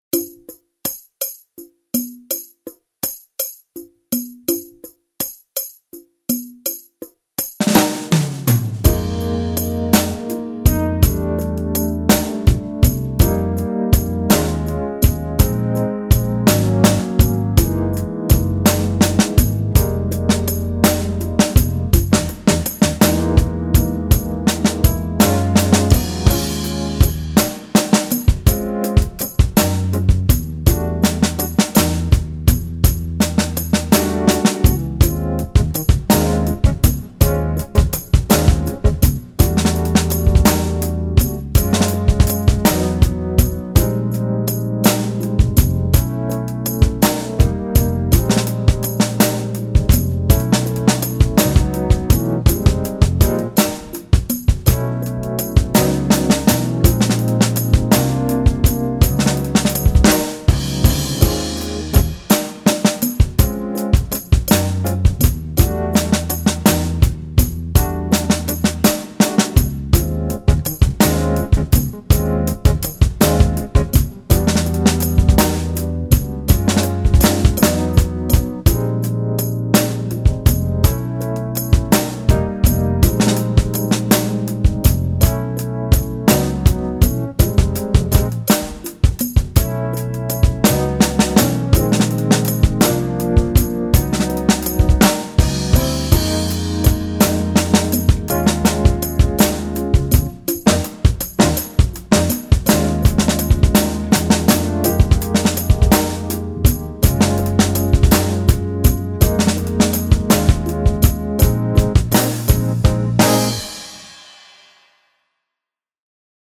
Esercizi per basso e batteria
TEMPI TERNARI.mp3